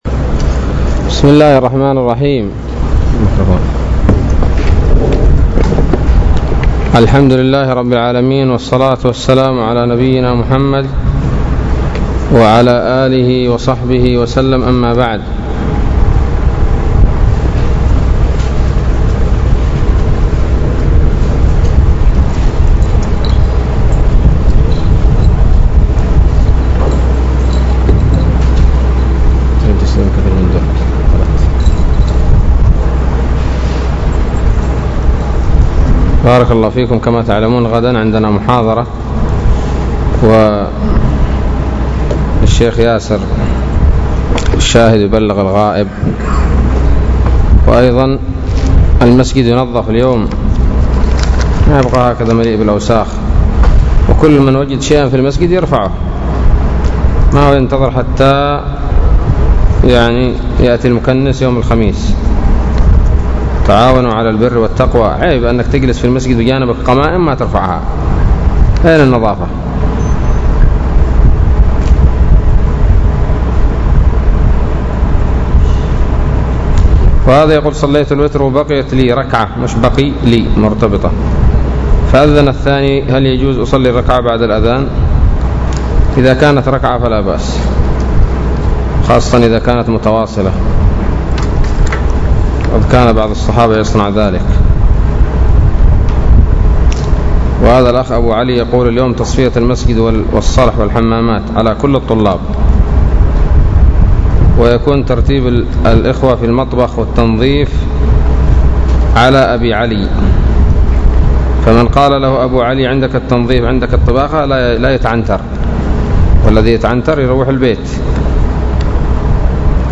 الدرس الثالث والخمسون من سورة الأنعام من تفسير ابن كثير رحمه الله تعالى